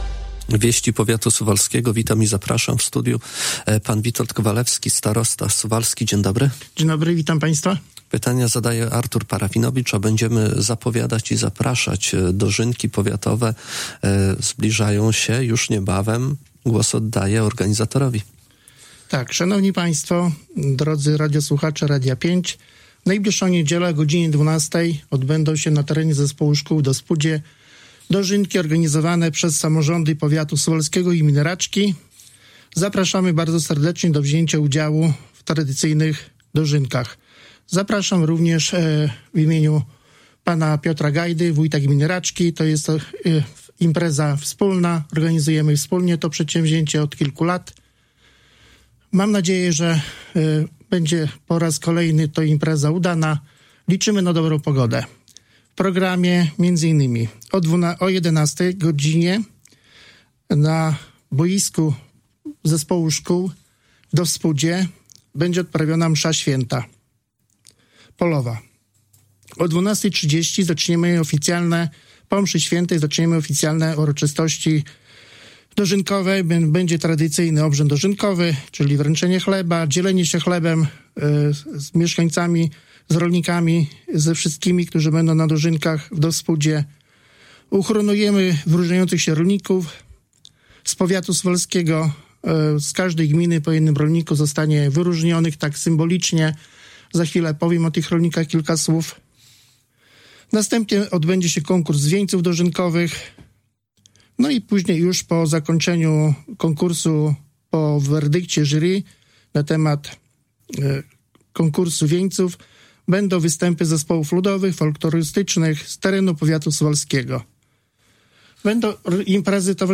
Cała rozmowa poniżej:
Później tradycyjne obrzędy dożynkowe, uhonorowanie wyróżniających się rolników, konkurs wieńców dożynkowych, występy zespołów, kiermasz jadła i rzemiosła, wystawa maszyn rolniczych oraz produkty regionalne i zabawy dla dzieci. Na wydarzenie zapraszał Witold Kowalewski, starosta suwalski.